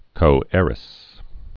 (kō-ârĭs)